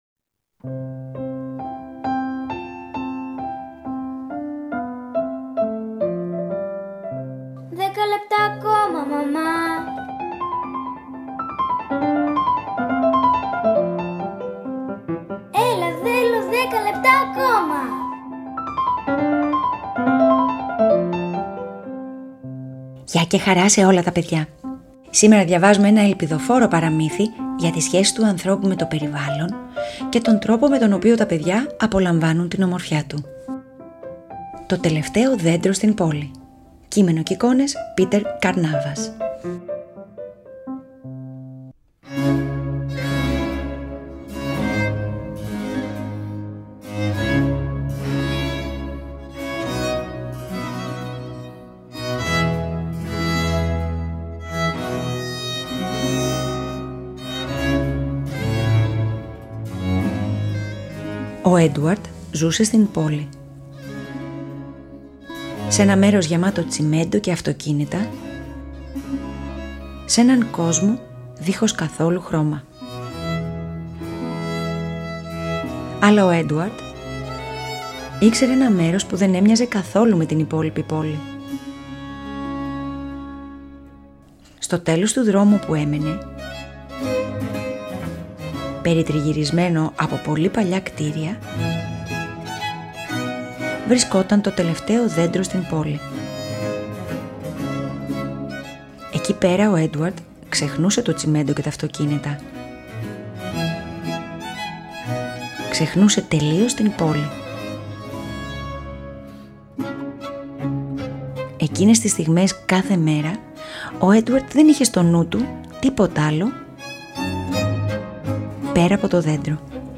Αφήγηση-Μουσικές επιλογές
ΠΑΡΑΜΥΘΙΑ